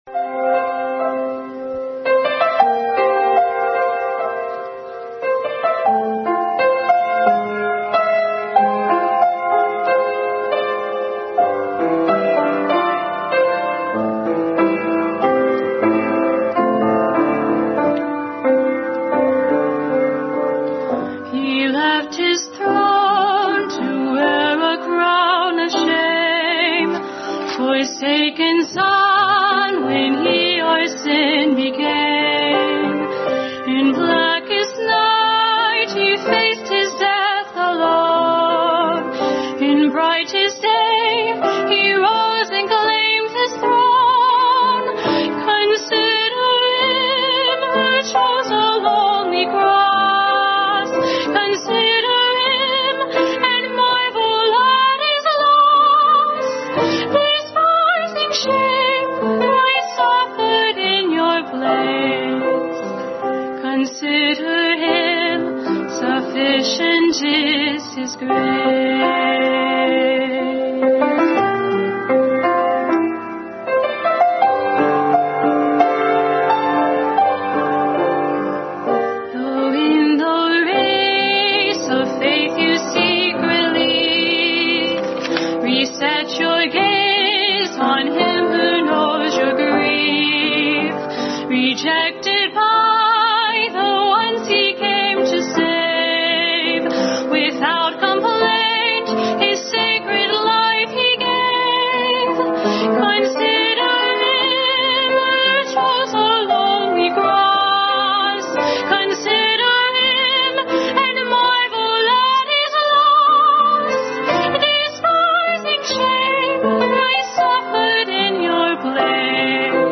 Family Bible Hour Message.
Preceded by special music, “Consider Him”